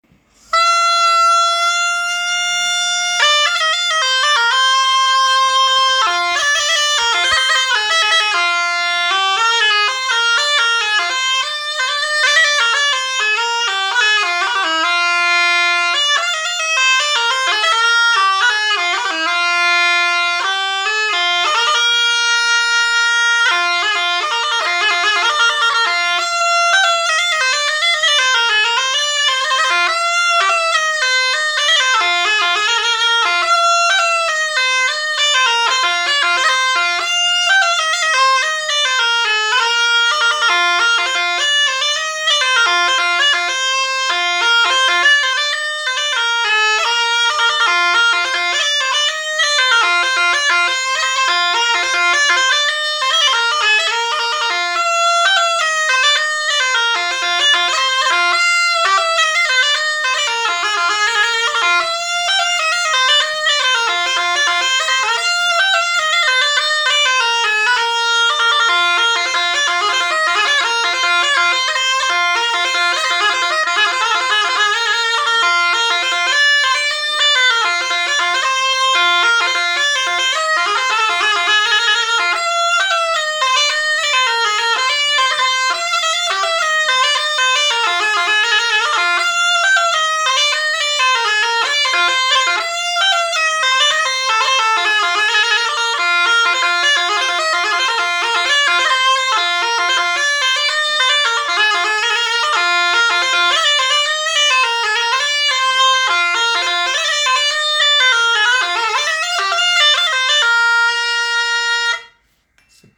Lieu : Toulouse
Genre : morceau instrumental
Instrument de musique : cabrette
Danse : bourrée